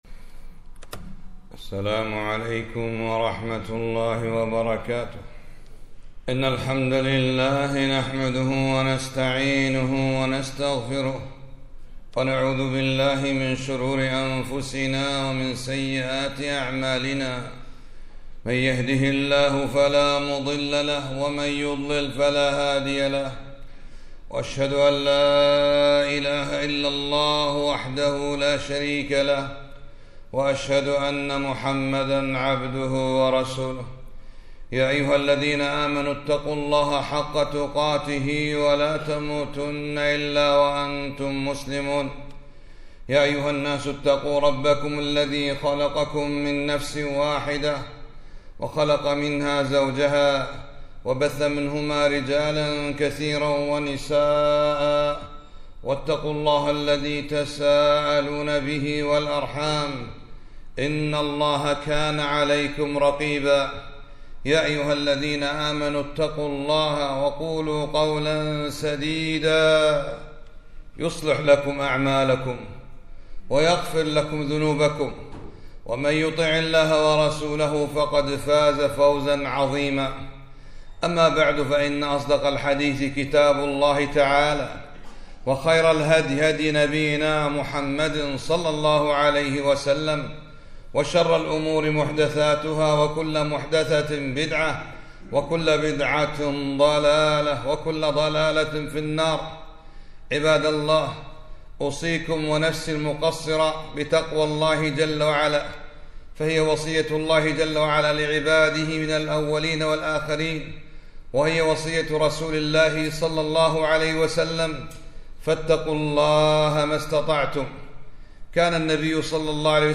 خطبة - ثلاث أدعية لا تفرط فيها